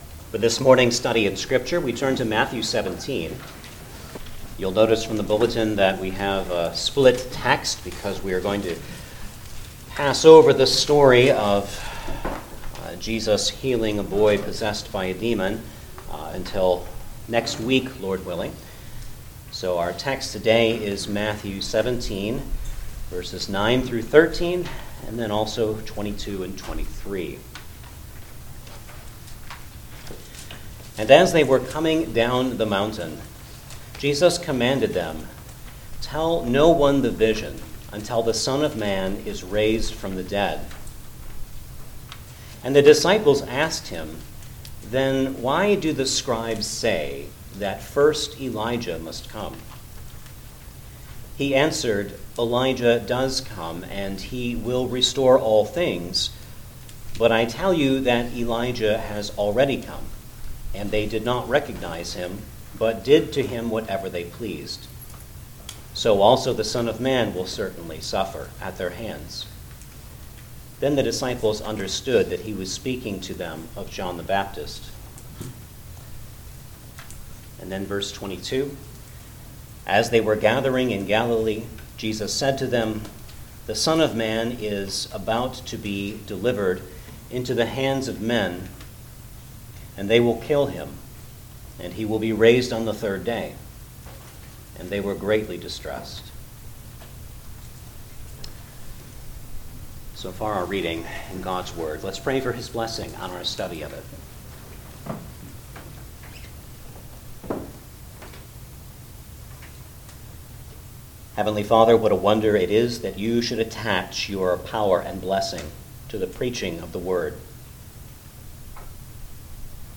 Gospel of Matthew Passage: Matthew 17:9-13, 22-23 Service Type: Sunday Morning Service Download the order of worship here .